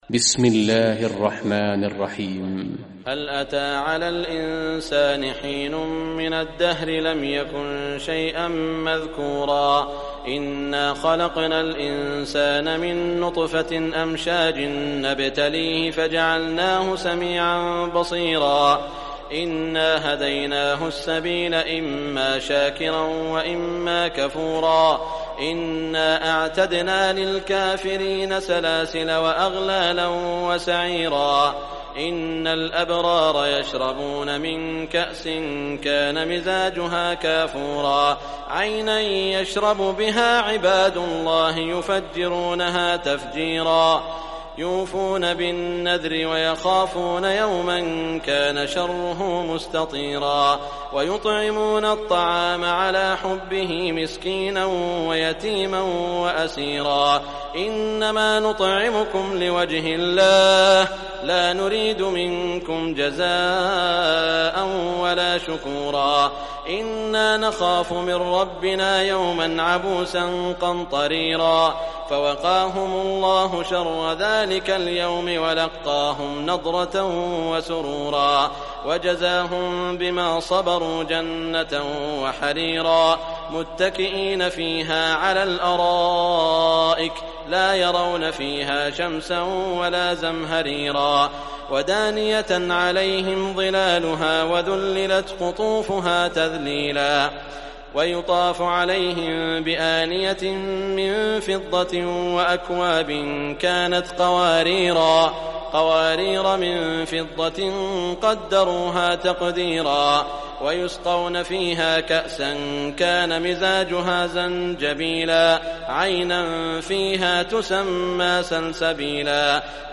Surah Insan Recitation by Sheikh Shuraim
Surah Insan, listen or play online mp3 tilawat / recitation in Arabic in the beautiful voice of Sheikh Saud al Shuraim.